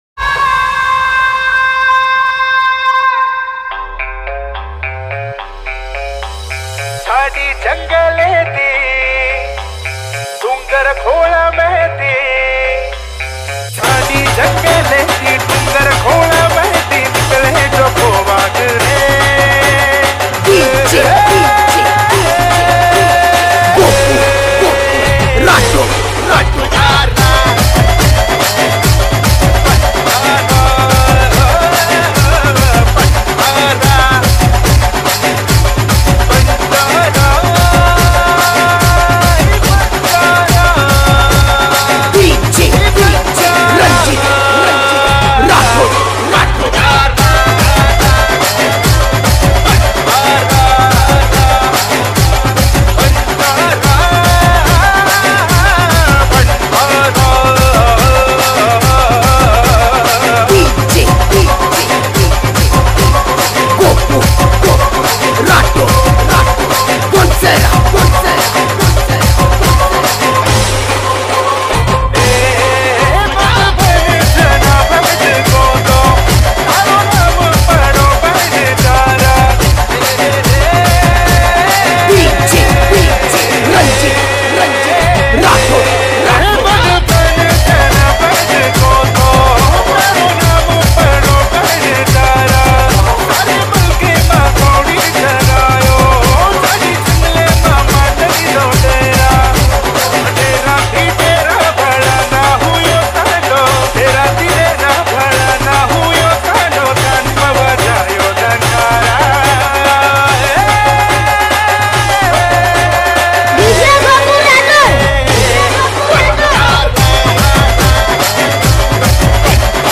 BANJARA DJ SONG 0